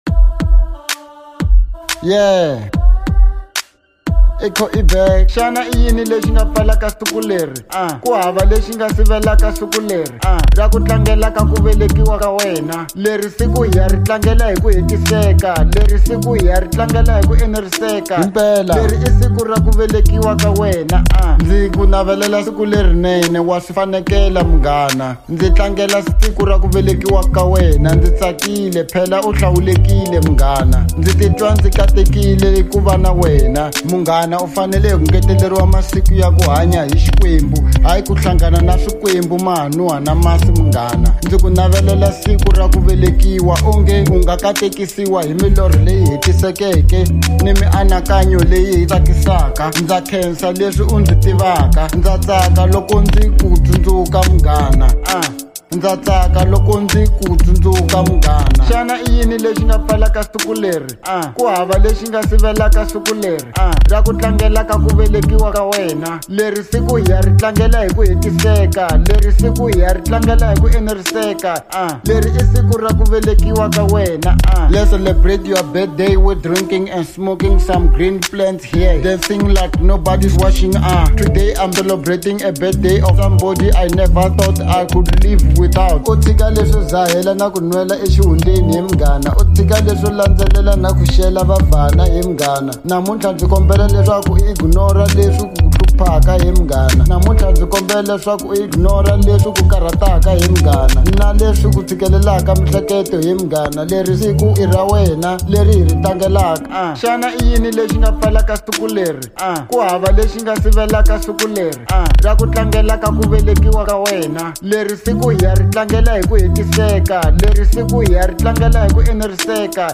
02:16 Genre : Hip Hop Size